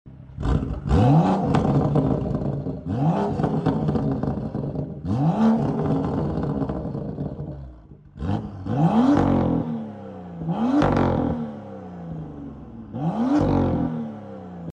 N55= bester 6 Zylinder sound sound effects free download
N55= bester 6 Zylinder sound von Bmw